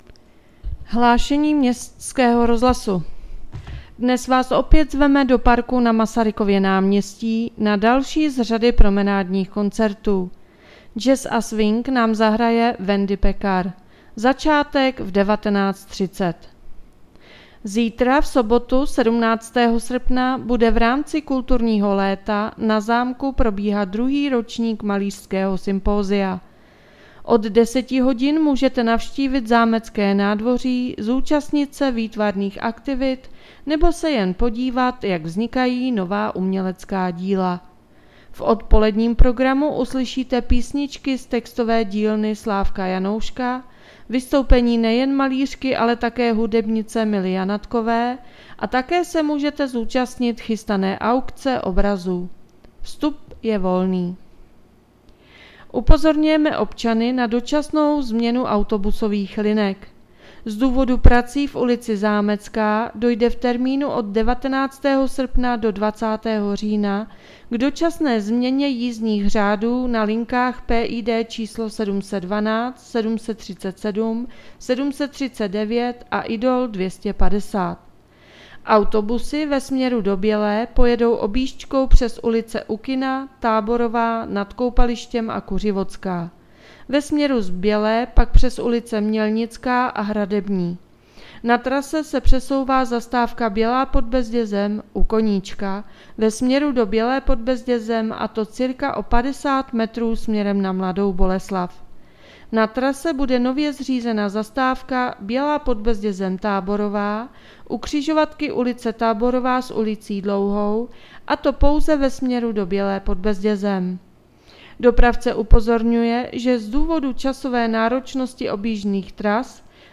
Hlášení městského rozhlasu 16.8.2024